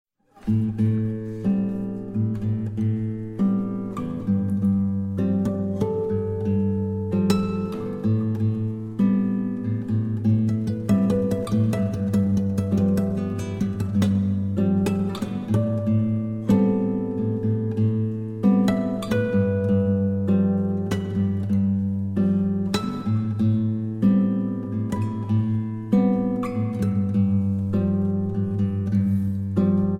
Shimmering Guitar and Lute Duets Composed and Improvised